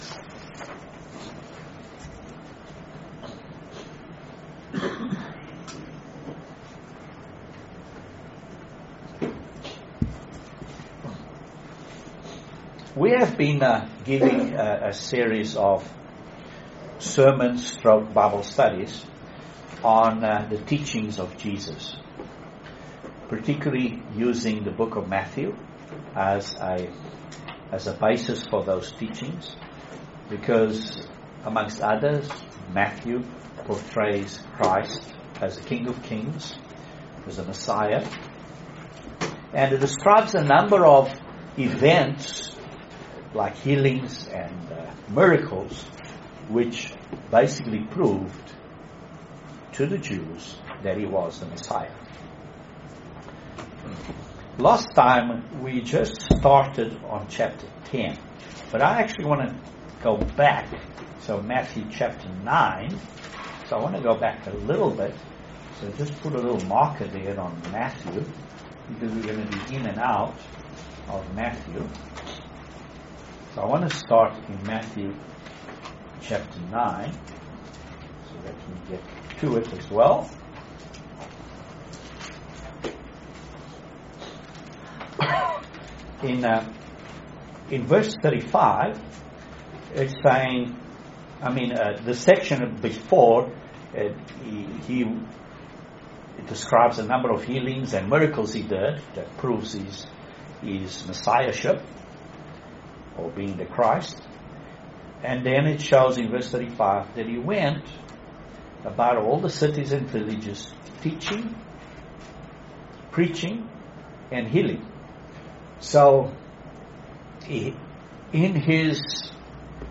Excellent Bible study on the Book of Matthew chapter 10. Jesus Christ sent the 12 Apostles out to Preach the Kingdom of God , heal, and cast out demons.